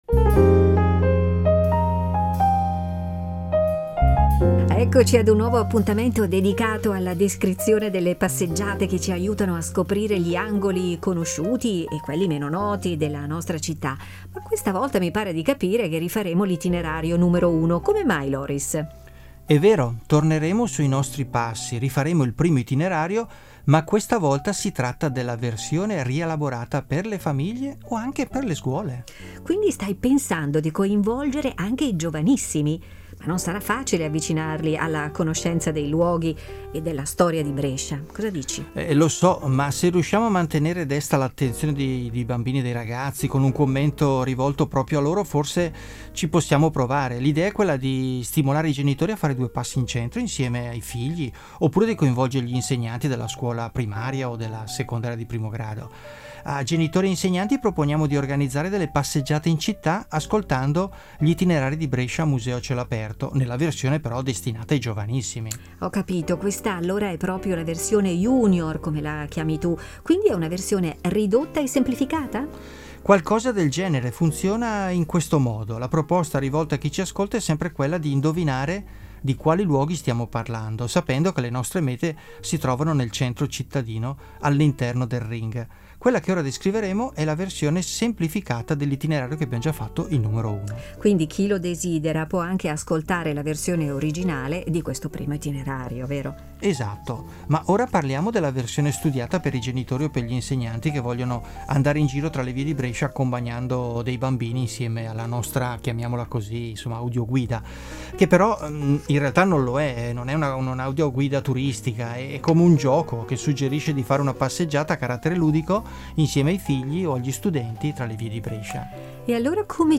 audio-guida junior e itinerari junior per passeggiare tra le vie di Brescia